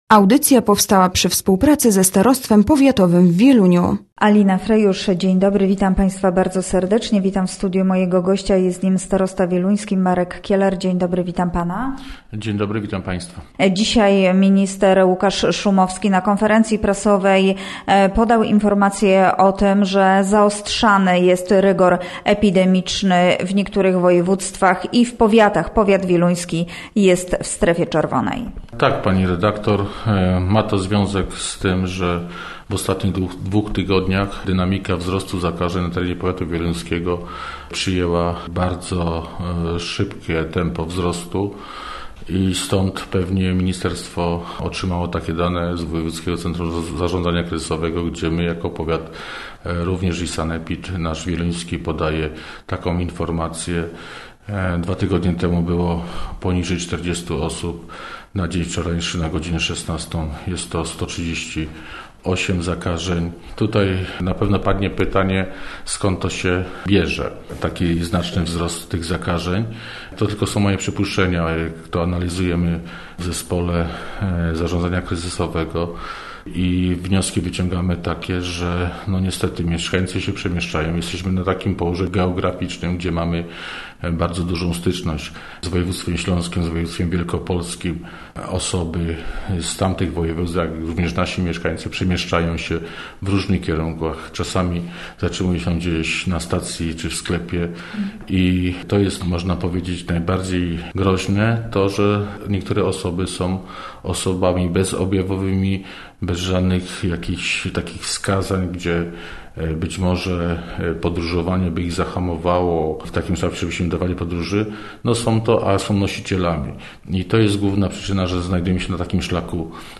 Gościem Radia ZW był Marek Kieler, starosta wieluński